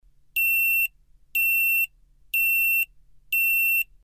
ZUMBADOR SONIDO CONTINUO O INTERMITENTE
Zumbador con sonido contínuo y/o intermitente
K40-Z-INTERMITENTE
K40-Z-INTERMITENTE.mp3